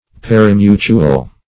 parimutuel - definition of parimutuel - synonyms, pronunciation, spelling from Free Dictionary
parimutuel \par`i*mu"tu*el\ (p[a^]r`[i^]*m[=u]"ch[=oo]*[u^]l),